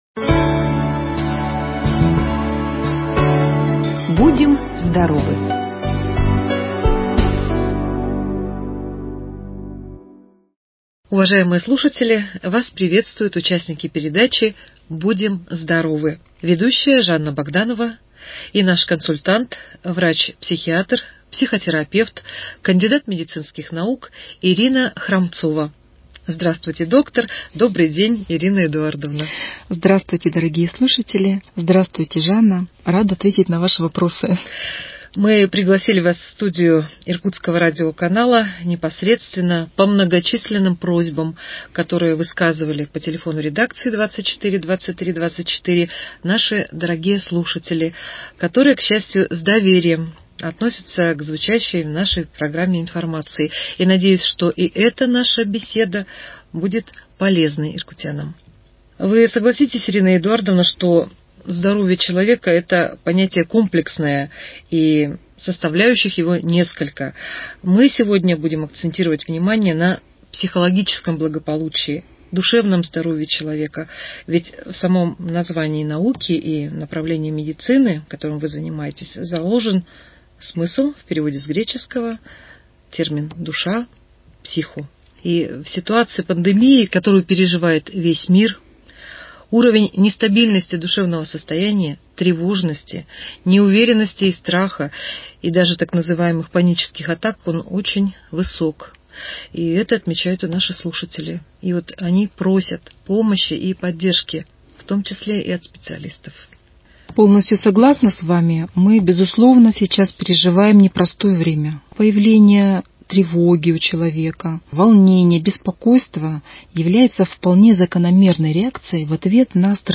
Передача «Будем здоровы». По просьбам иркутян, испытывающих в период пандемии стресс, тревожность, страх и др. негативные состояния психики, записали передачу с участием специалиста по профилактике и лечению таких проблем со здоровьем.